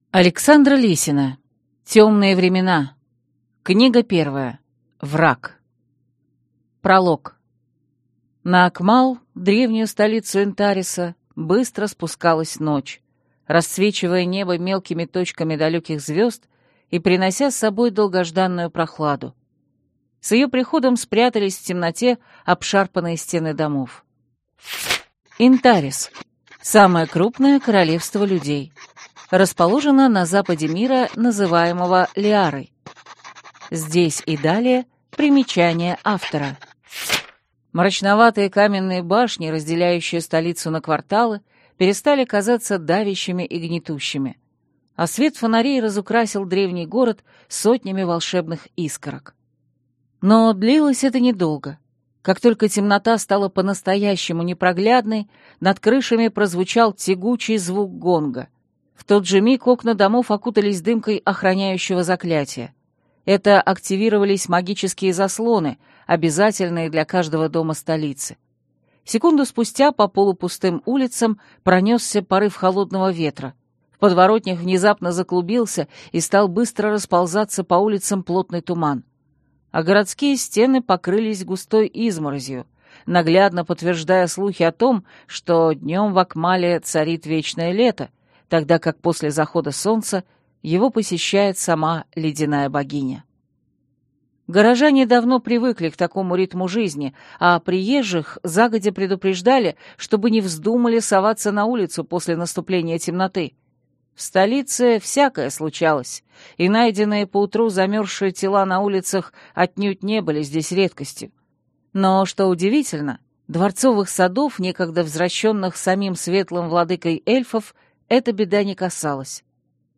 Аудиокнига Темные времена. Враг | Библиотека аудиокниг